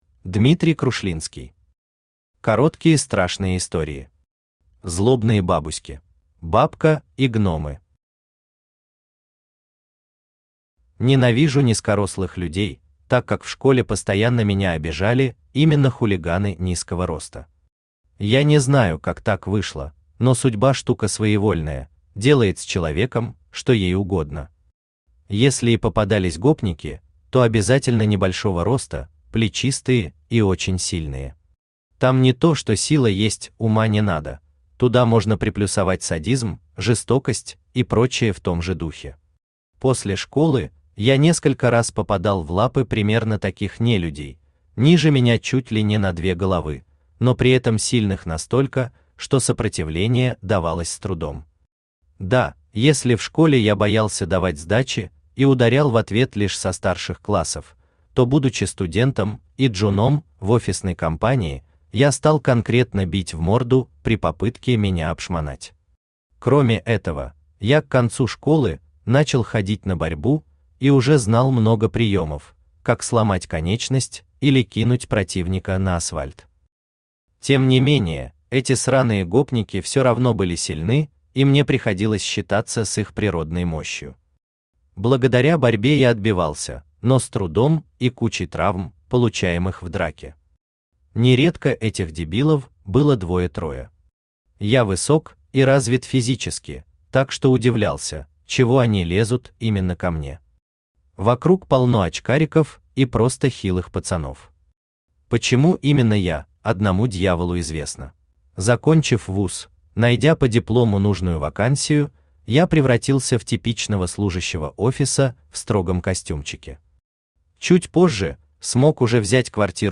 Аудиокнига Короткие страшные истории. Злобные бабуськи | Библиотека аудиокниг
Злобные бабуськи Автор Дмитрий Сергеевич Крушлинский Читает аудиокнигу Авточтец ЛитРес.